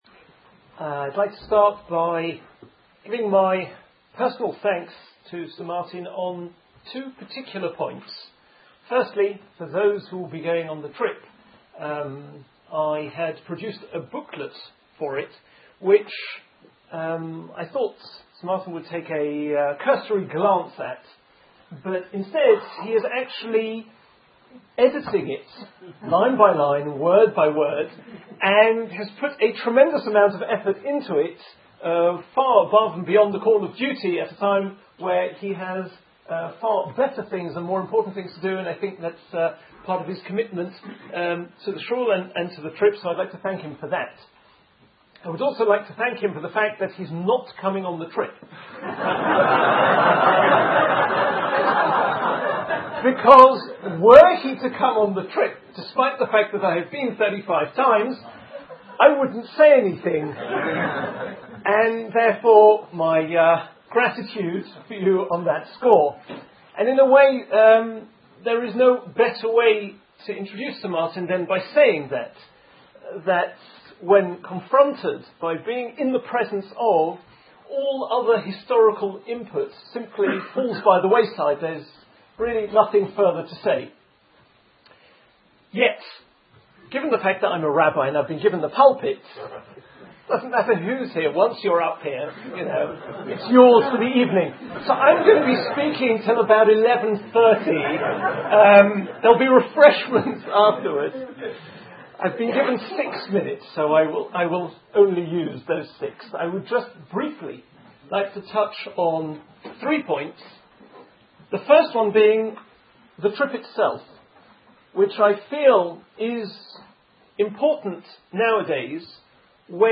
Sir Martin Gilbert Introduction